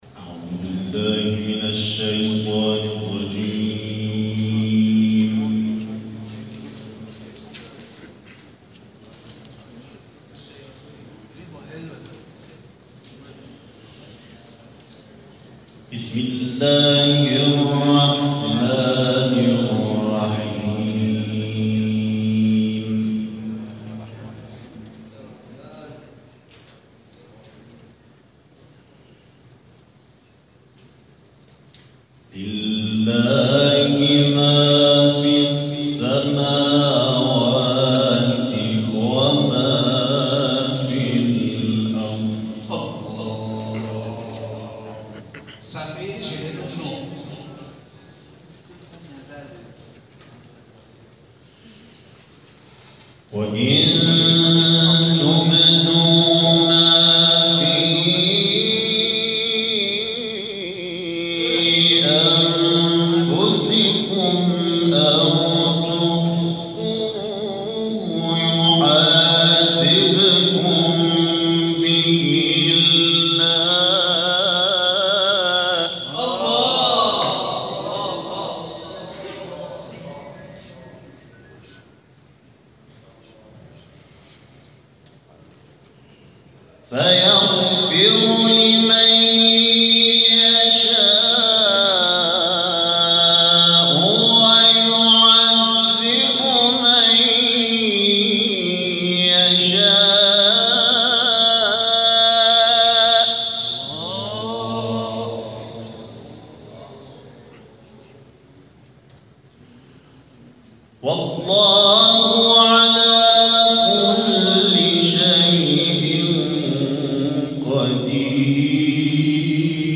در ادامه تلاوت‌های منتخب این جلسه ارائه می‌شود.